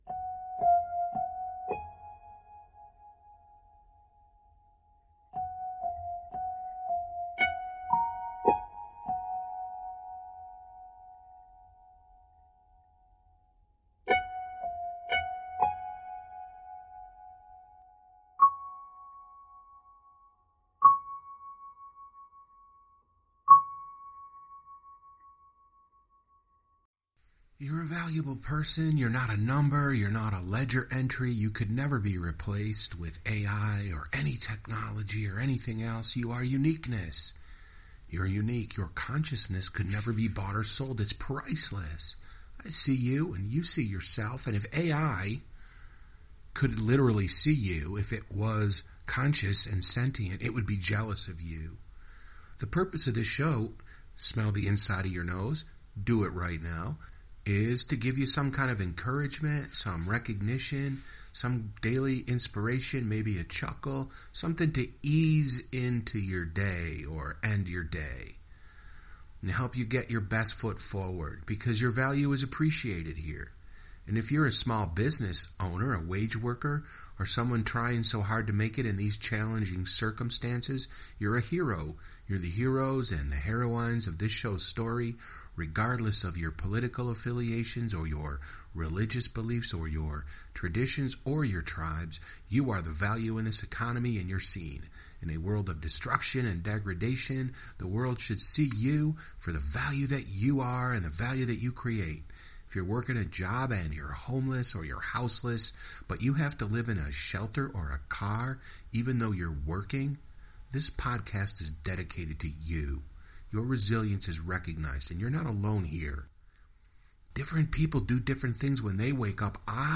This is my first Flash Fiction story recorded on location.